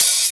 18 OP HAT.wav